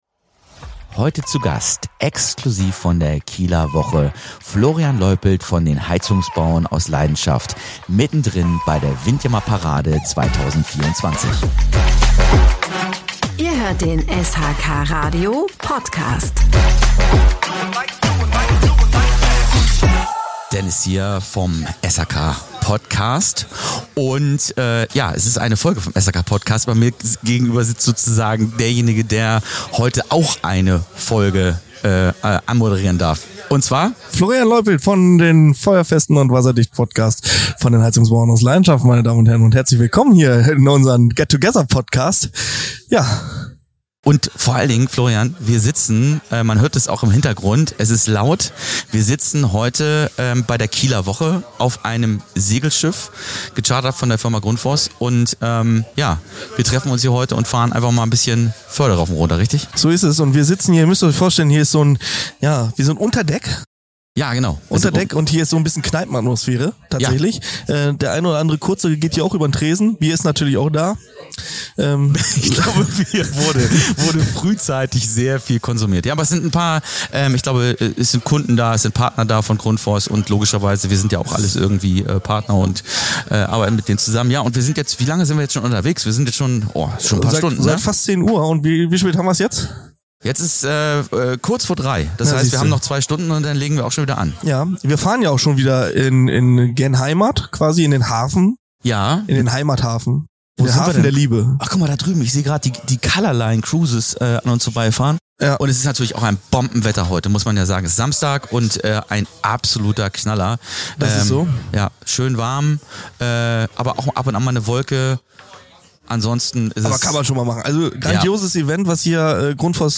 Die Aufnahme findet während der pulsierenden Kieler Woche statt, an Bord von Grundfos gecharterten Segelschiffs.